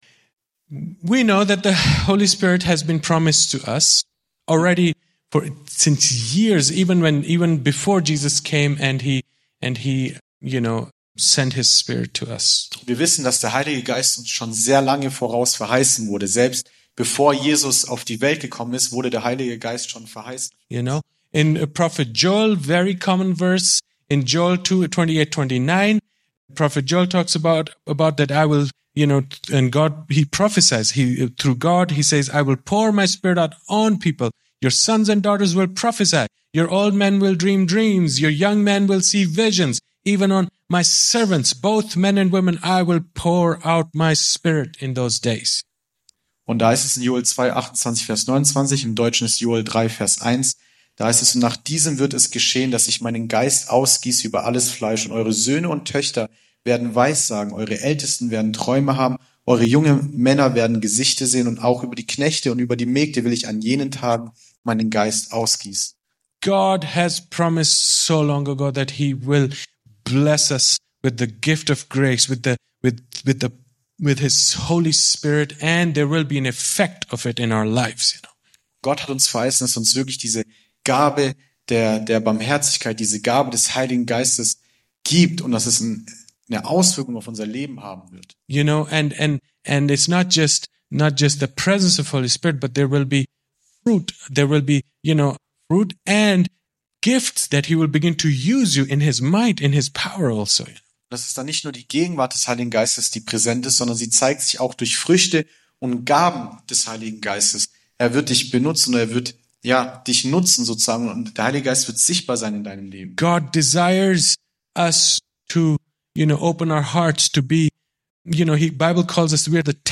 Vortrag
im Christlichen Zentrum Villingen-Schwenningen.